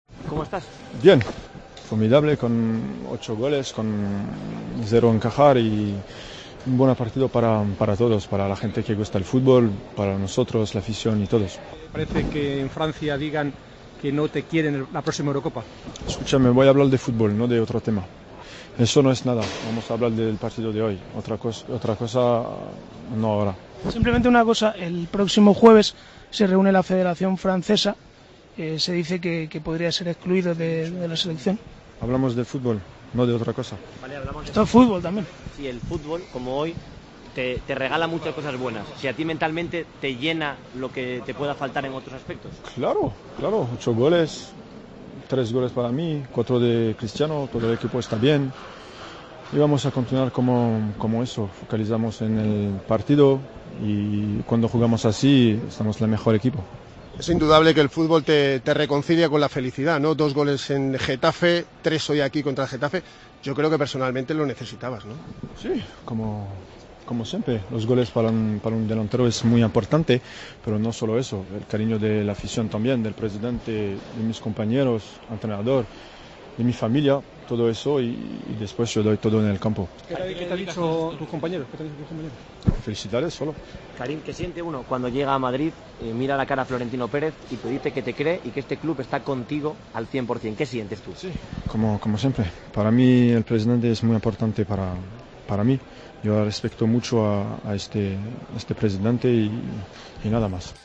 Redacción digital Madrid - Publicado el 09 dic 2015, 00:25 - Actualizado 17 mar 2023, 10:34 1 min lectura Descargar Facebook Twitter Whatsapp Telegram Enviar por email Copiar enlace El delantero francés, tras sus tres goles ante el Malmö, sólo quiso hablar de fútbol en zona mixta.